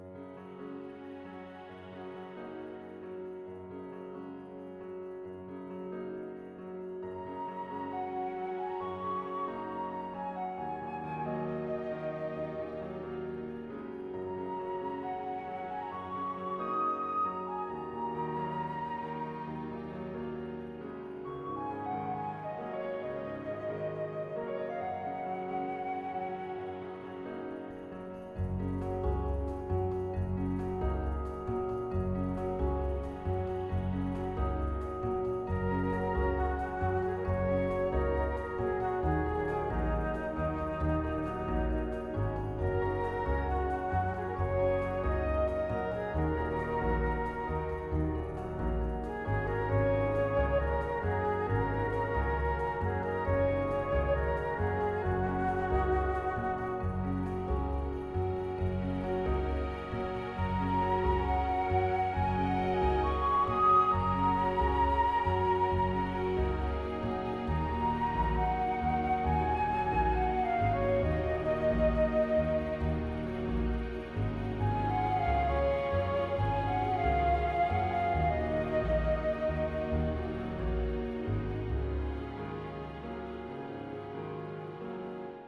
• Music requires/does smooth looping